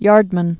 (yärdmən)